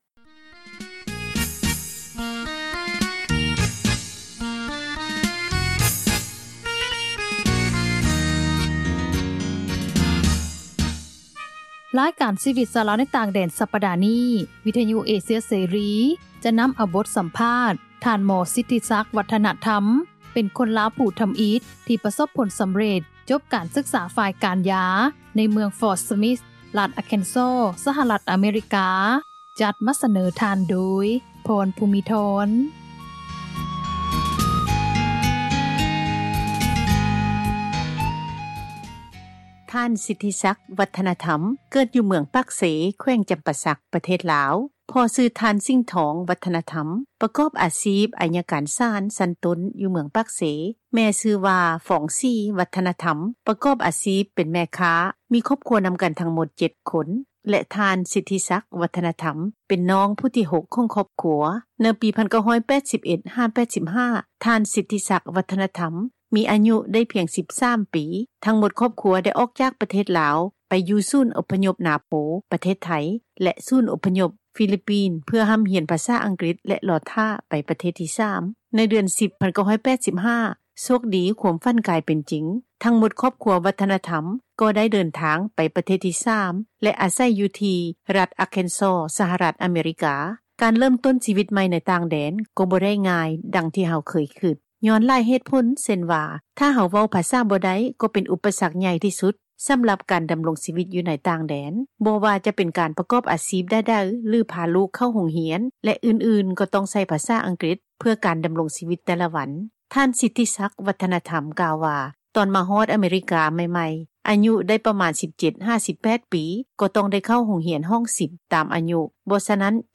ຣາຍການຊີວິດ ຊາວລາວໃນຕ່າງແດນ ສັປດານີ້, ວິທຍຸເອເຊັຽເສຣີ ຈະນໍາເອົາບົດສັມພາດ